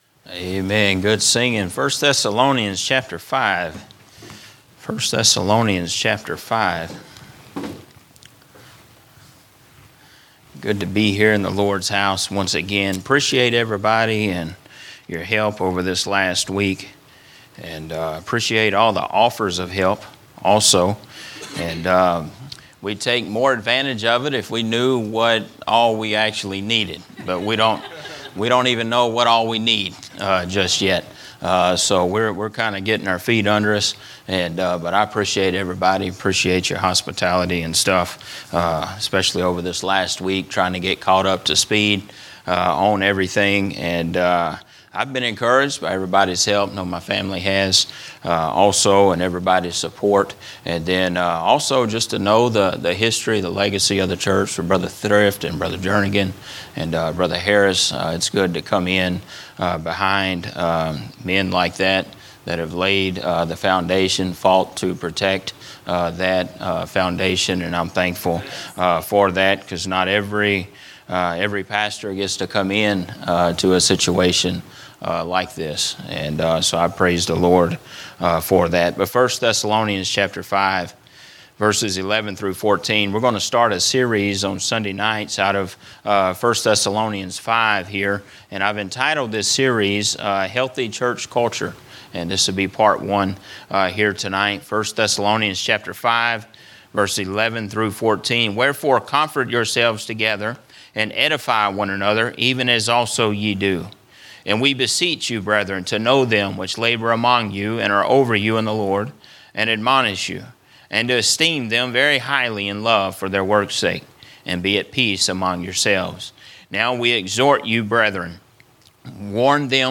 A message from the series "General Preaching." A look at the story of Jesus healing the ten lepers